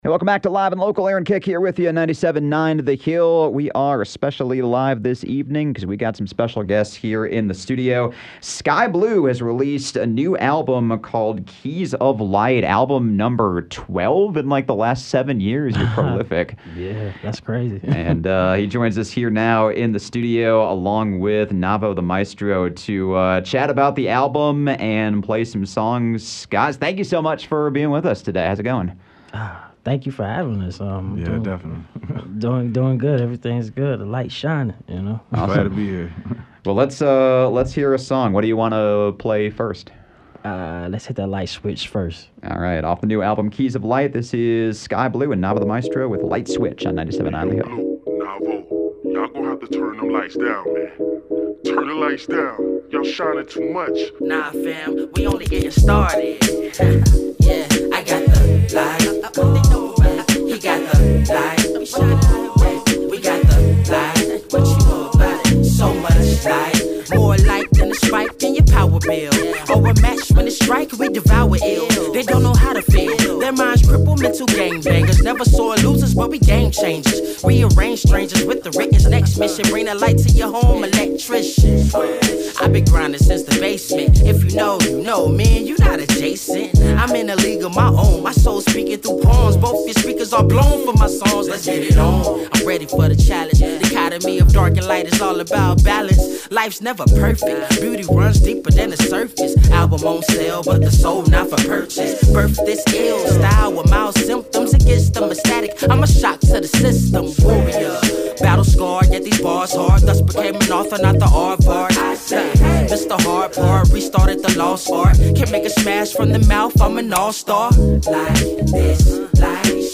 Local hip-hop artists